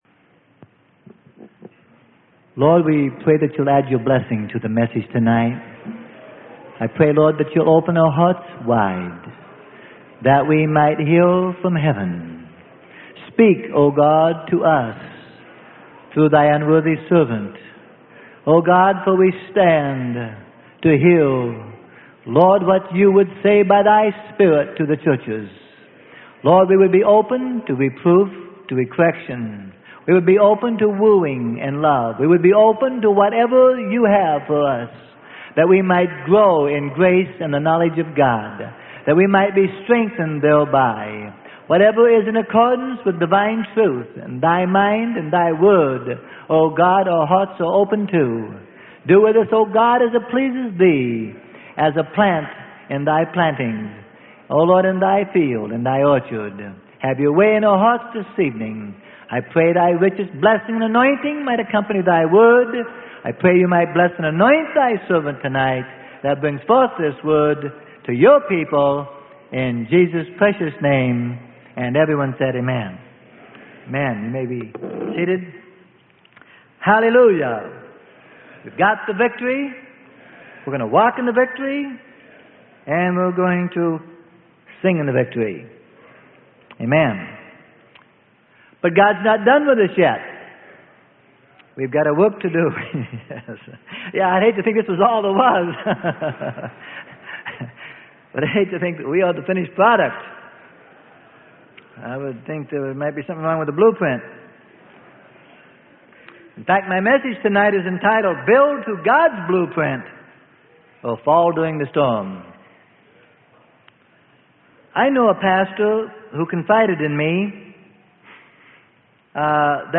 Sermon: Build To God'S Blueprint Or Fall During The Storm.